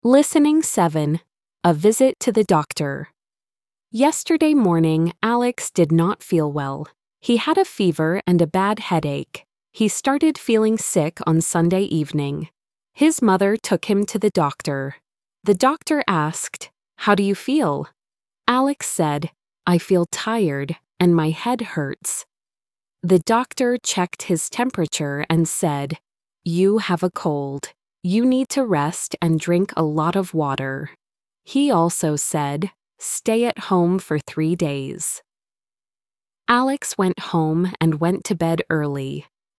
The story includes a short conversation.